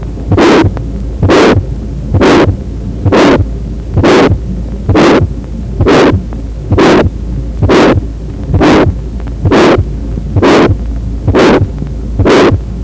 single S2,  grade 3/6 systolic ejection murmur at MLSB คลำตับได้ 3 cm ต่ำกว่าชายโครงขวา